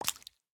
sounds / mob / frog / tongue2.ogg
tongue2.ogg